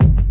Kick (Kanye-1).wav